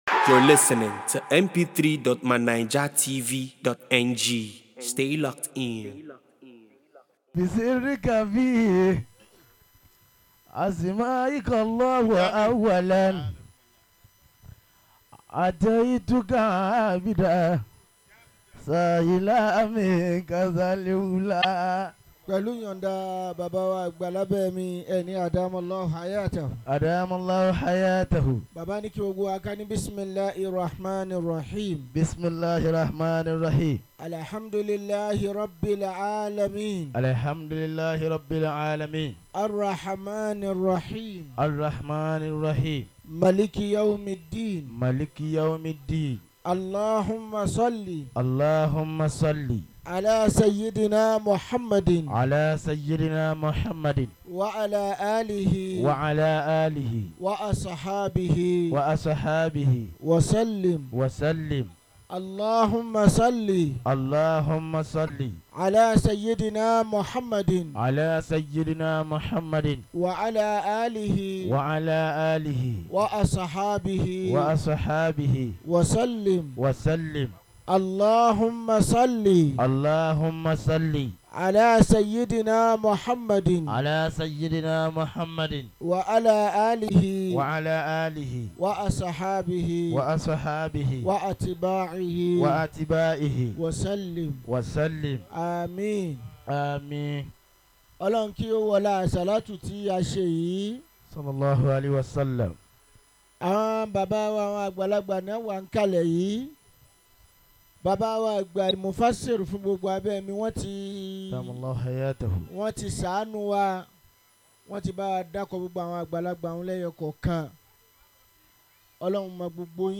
at ABODE MECCA